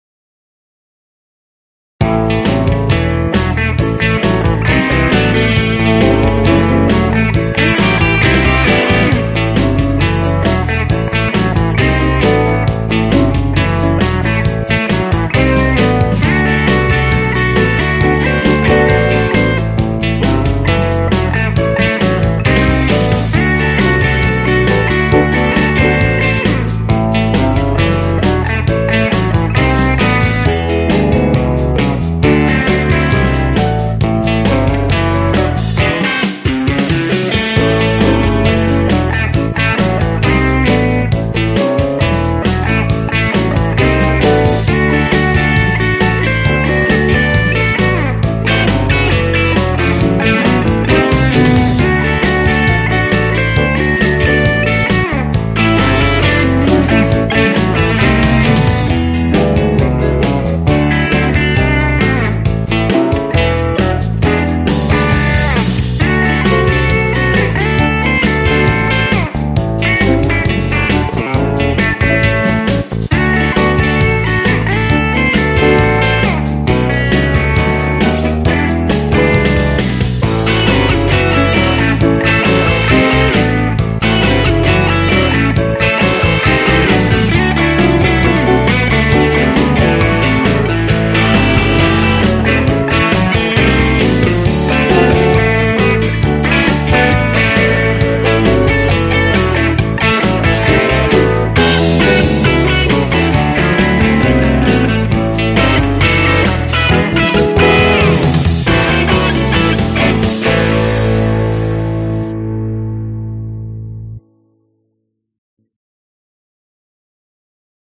描述：乡村摇滚在135 BPM预览是所有文件的粗略混合。所有文件都从1两小节开始计数。
Tag: 贝斯 蓝调 乡村 吉他 钢琴 摇滚